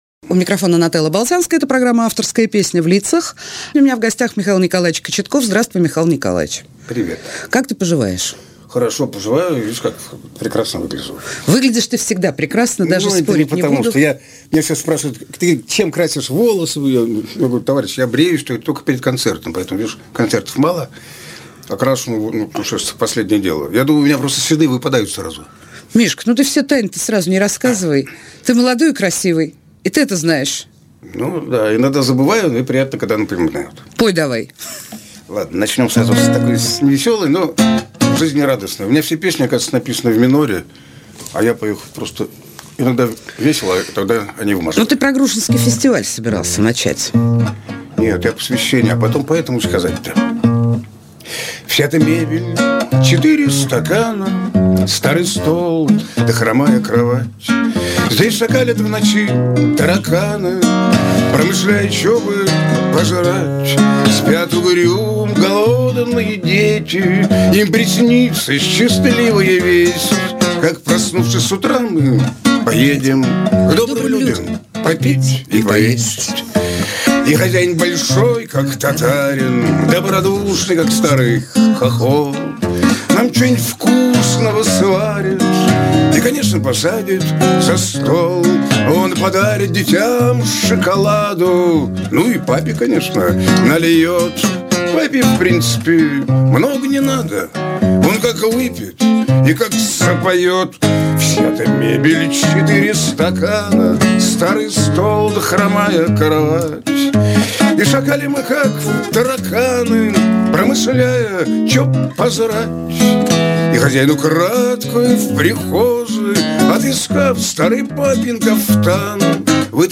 Российский поэт,гитарист,бард.
Жанр: Авторская песня